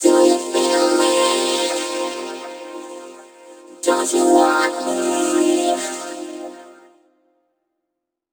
VVE1 Vocoder Phrases
VVE1 Vocoder Phrases 17.wav